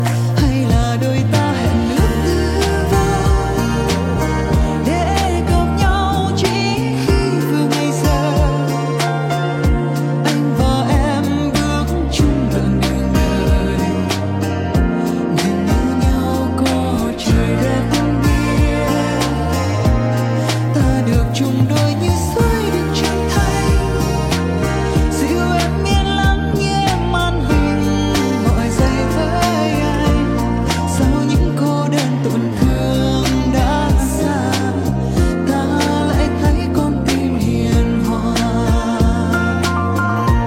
thể loại Nhạc Trẻ